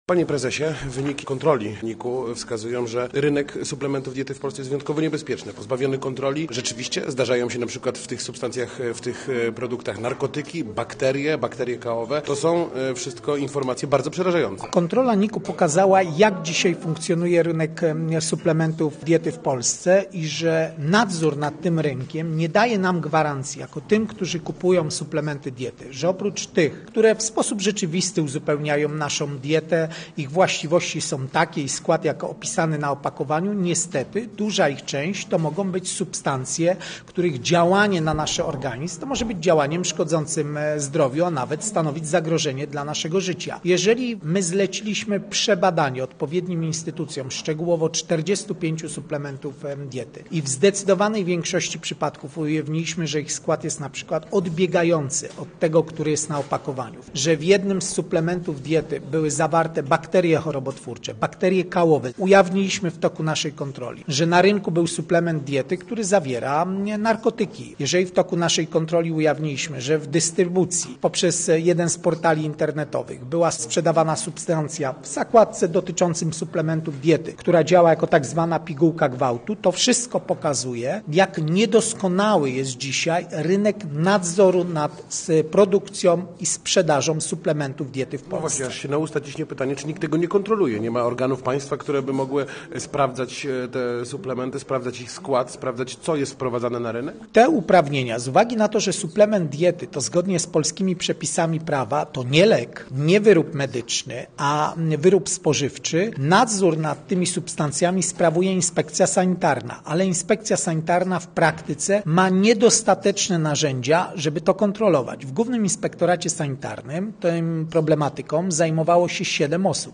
Posłuchaj rozmowy z prezesem NIK Krzysztofem Kwiatkowskim: Nazwa Plik Autor Rozmowa z Krzysztofem Kwiatkowskim audio (m4a) audio (oga) Warto przeczytać Śmiertelne potrącenie w Sieradzu na DK 83 2 lipca 2025 To jest temat.